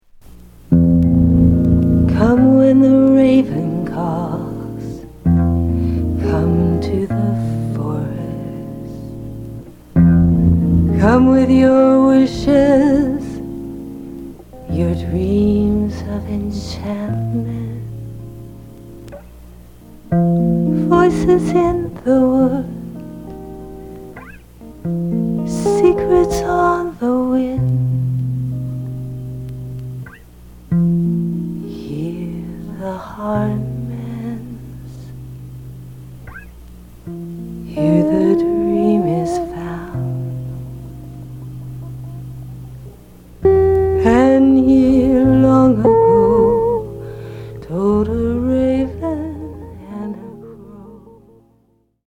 じっとりと湿った夜のメランコリー。
即興　フォーク